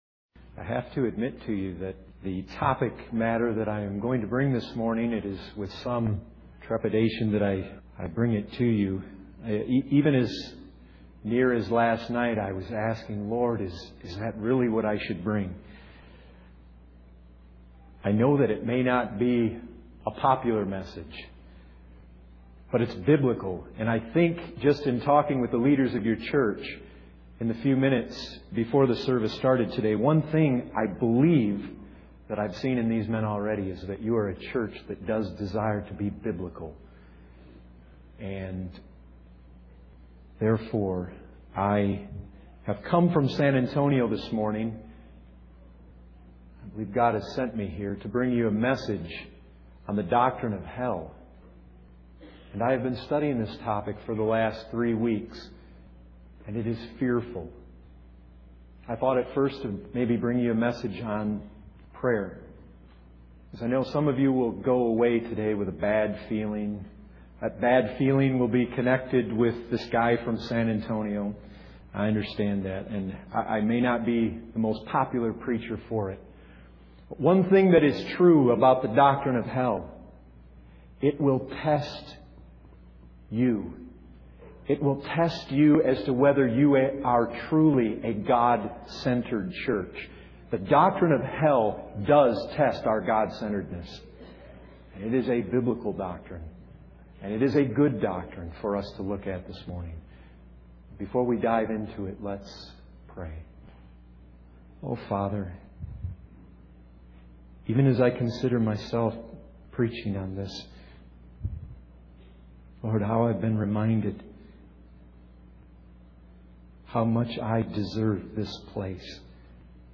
This sermon delves into the doctrine of hell, emphasizing the biblical truth of eternal punishment for those who reject God. It challenges the audience to consider the severity, security, suitability, and sanctity of hell, highlighting the importance of God's glory and justice. The speaker urges listeners to repent and turn to Christ before it's too late, underscoring the eternal consequences of sin and the need for a proper understanding of God's holiness and wrath.